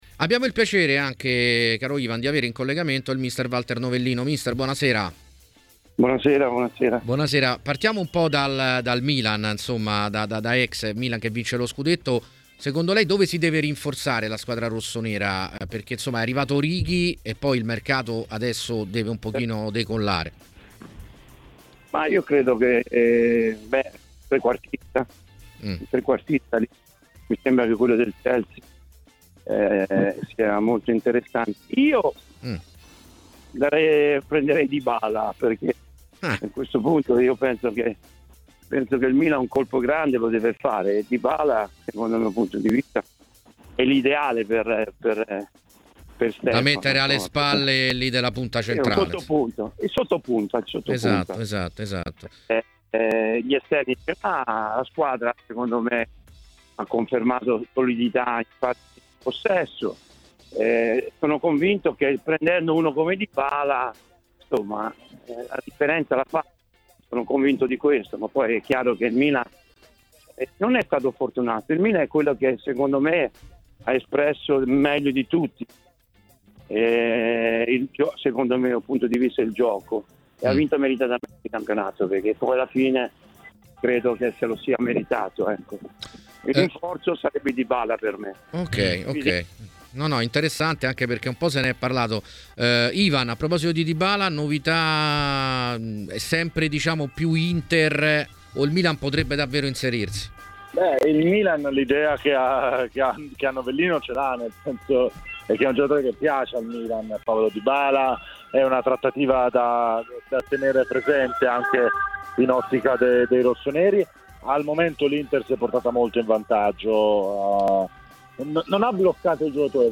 Nel suo intervento a TMW Radio, Walter Novellino ha parlato dei tanti giocatori che non riescono a trovare squadra anche se a parametro zero, a volte anche per le richieste alte dei loro procuratori:...